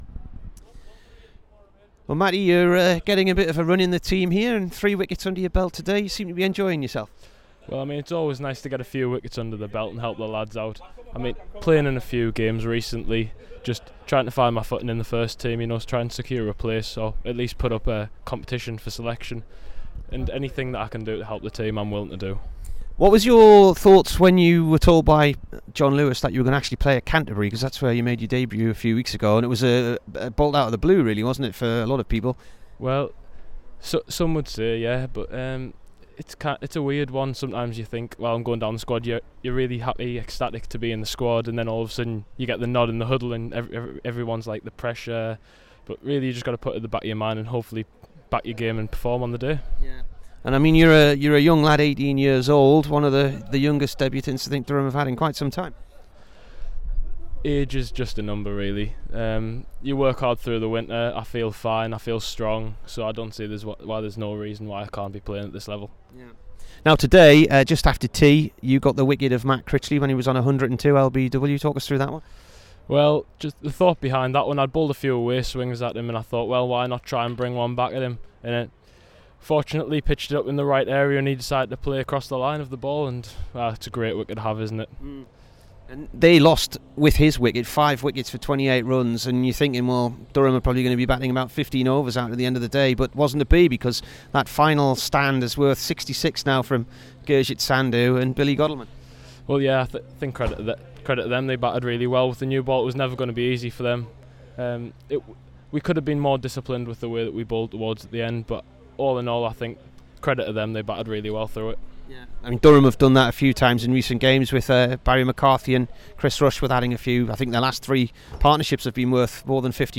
Here's the Durham bowler after he took 3 wickets on day one in Chesterfield.